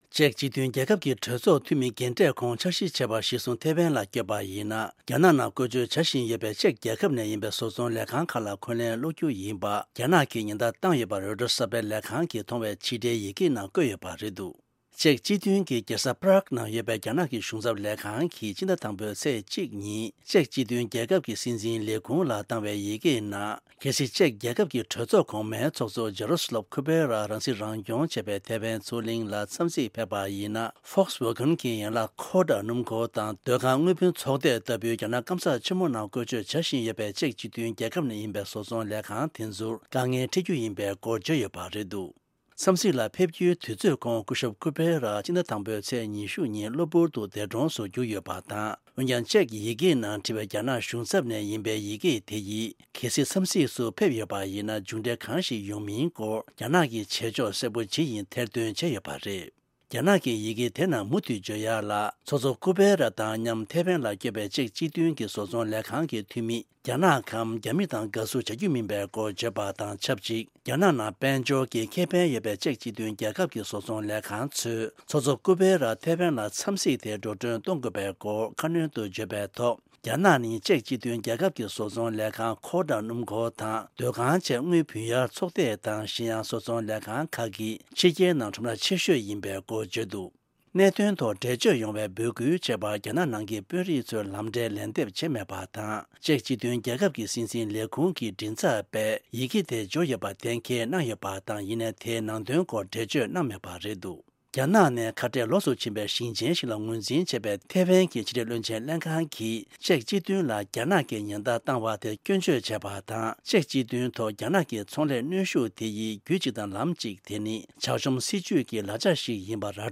ཕབ་སྒྱུར་དང་སྙན་སྒྲོན་ཞུ་ཡི་རེད།